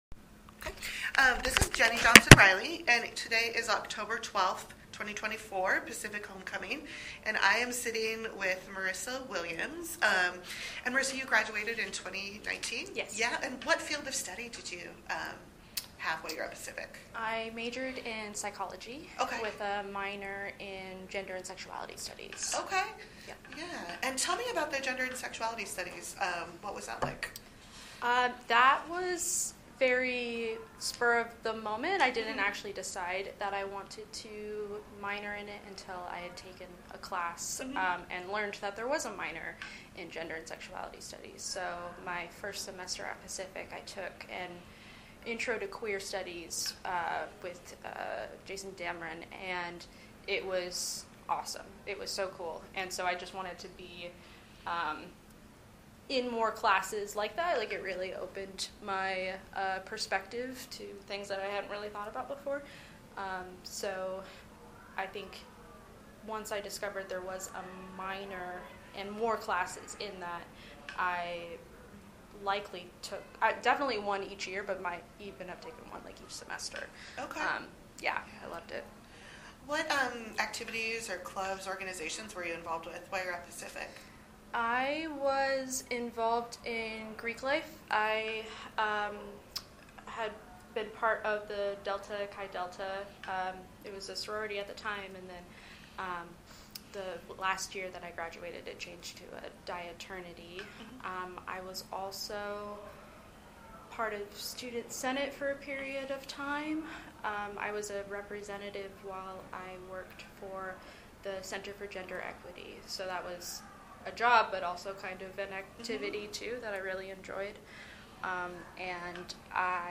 oral history
This is one of a group of recordings made during a reunion in October, 2024.